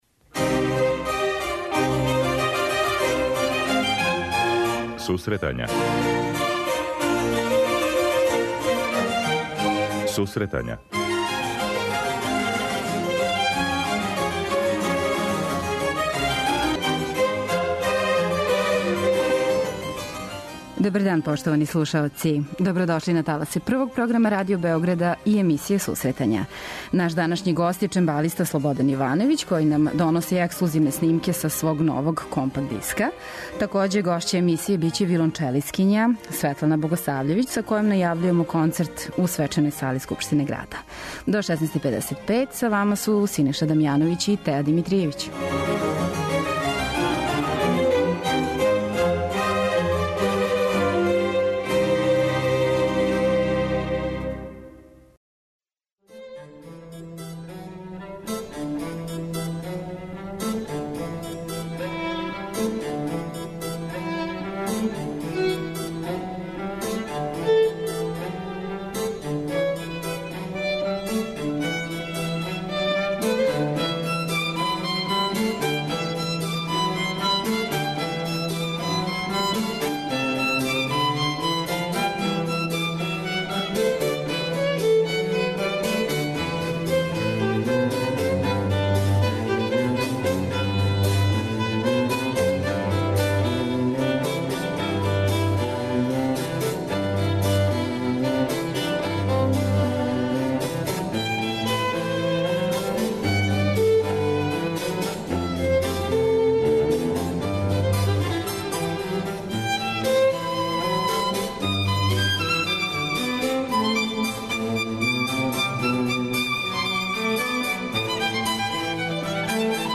преузми : 26.36 MB Сусретања Autor: Музичка редакција Емисија за оне који воле уметничку музику.